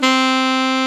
Index of /90_sSampleCDs/Roland L-CDX-03 Disk 1/SAX_Alto Short/SAX_A.mf 414 Sh
SAX A.MF C05.wav